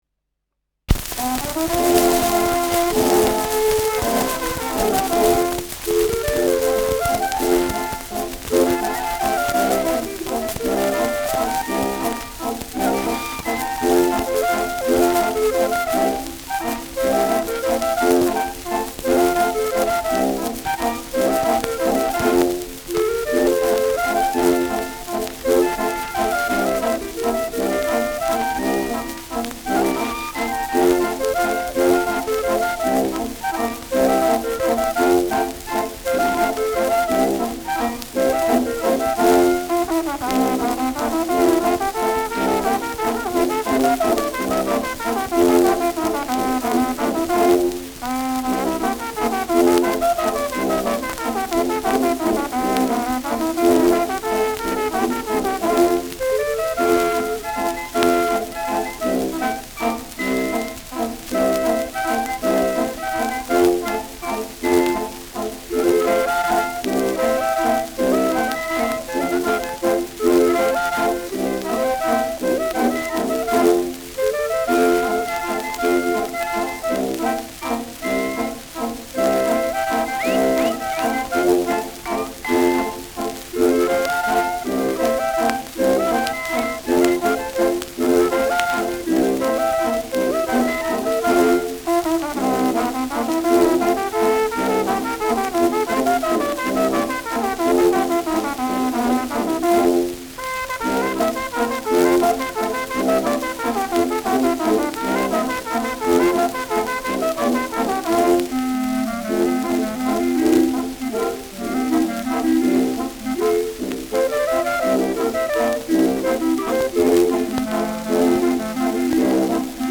Schellackplatte
präsentes Rauschen : Knistern
Dachauer Bauernkapelle (Interpretation)